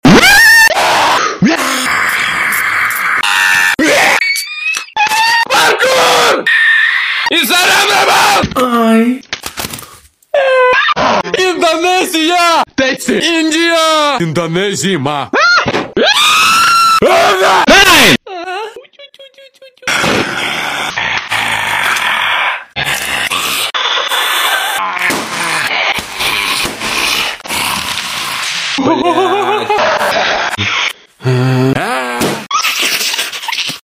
screams from compilation video part sound effects free download